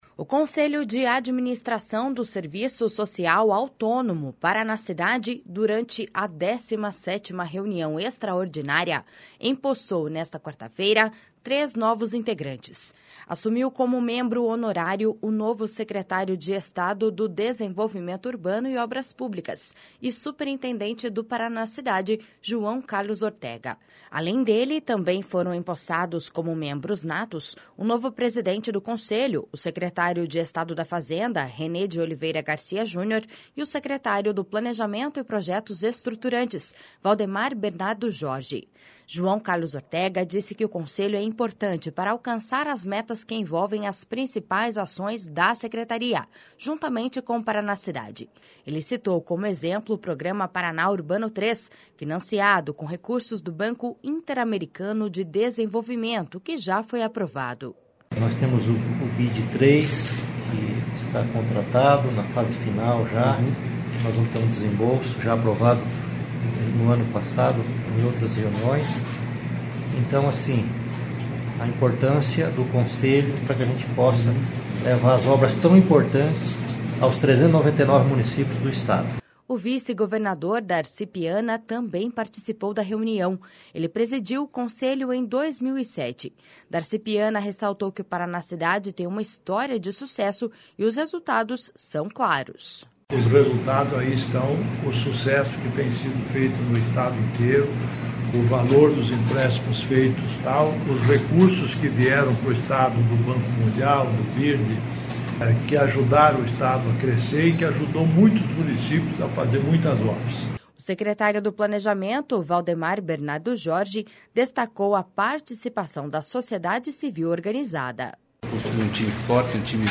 Darci Piana ressaltou que o Paranacidade tem uma história de sucesso e os resultados são claros.// SONORA DARCI PIANA.//
O secretário do Planejamento, Valdemar Bernardo Jorge, destacou a participação da sociedade civil organizada.// SONORA VALDEMAR BERNARDO JORGE.//
O secretário da Fazenda, Renê de Oliveira Júnior, afirmou que assumiu o compromisso com o governador Ratinho Junior de fazer uma gestão participativa e transparente.// SONORA RENÊ DE OLIVEIRA JÚNIOR.//